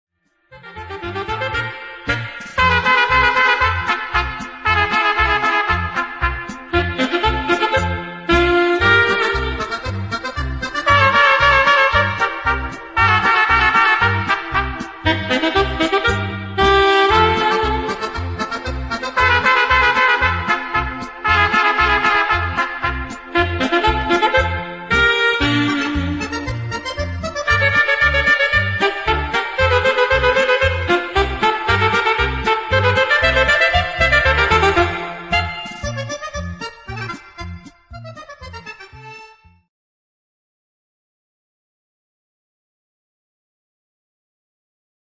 valzer viennese